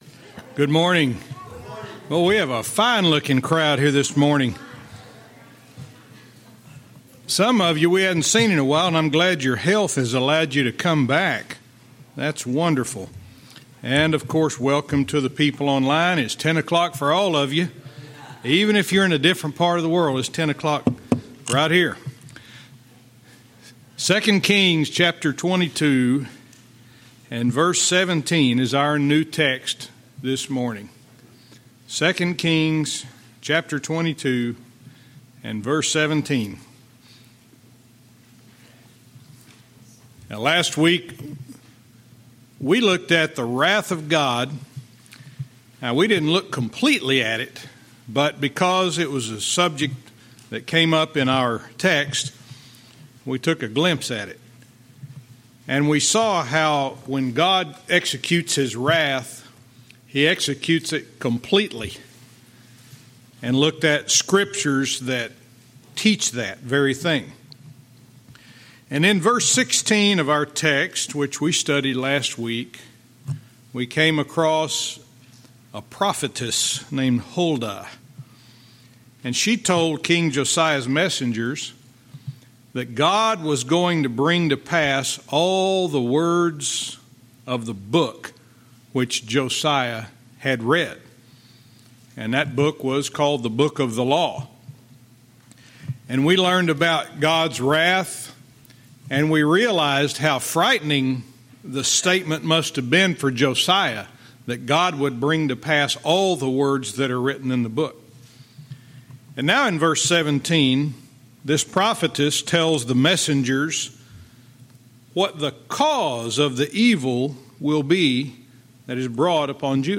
Verse by verse teaching - 2 Kings 22:17